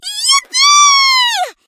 bonni_ulti_vo_03.ogg